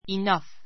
inʌ́f